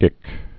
(ĭk)